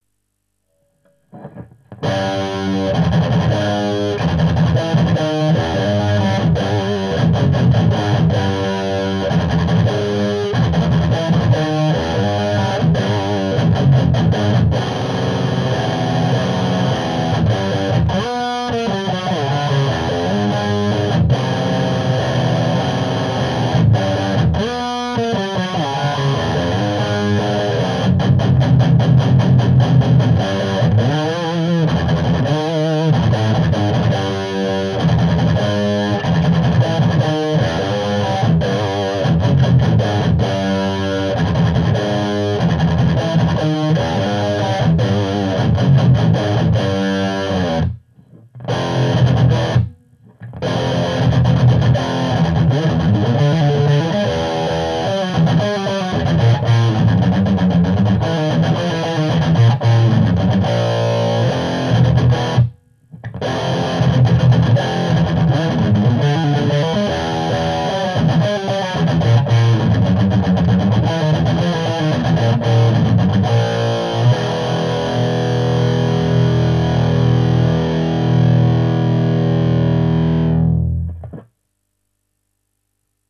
Les autres en modern: